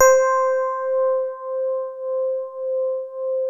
TINE HARD C4.wav